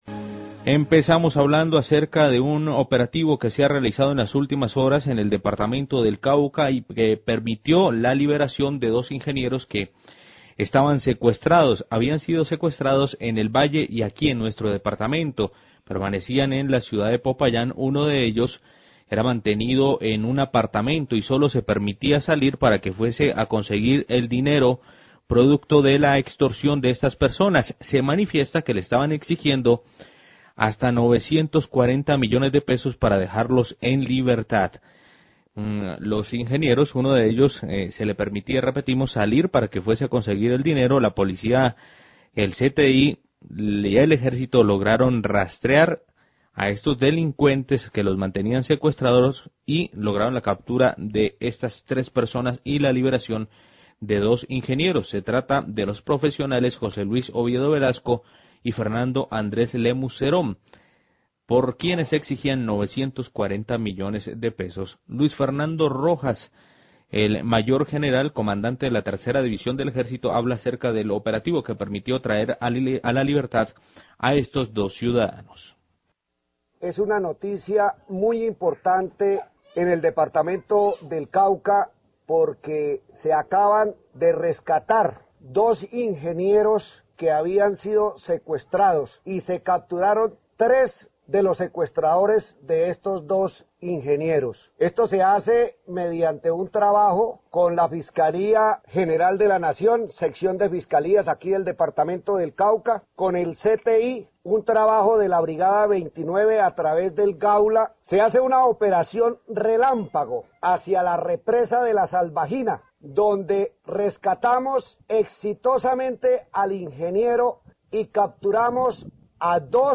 CDTE 3ra DIVISIÓN EJÉRCITO HABLA DEL RESCATE DE 2 INGENIEROS QUE ESTABAN SECUESTRADOS EN LA SALVAJINA
Radio